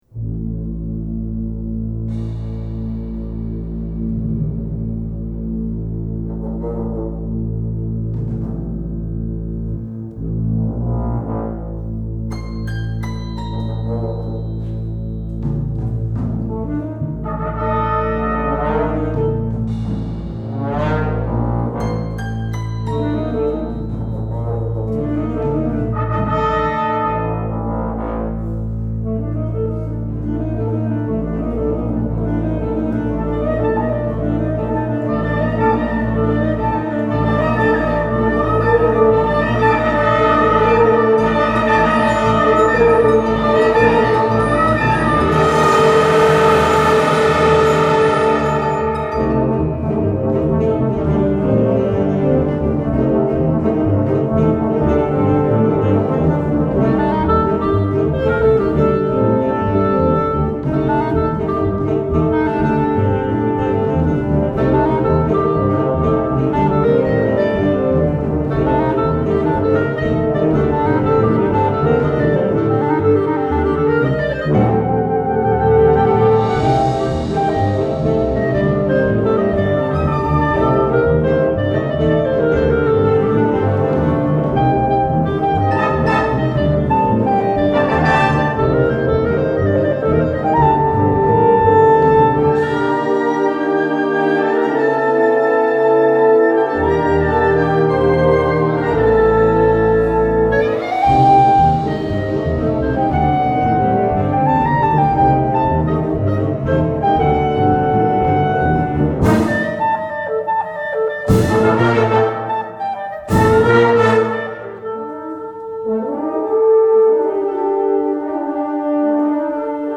Gattung: Solo für Klarinette und Blasorchester
Besetzung: Blasorchester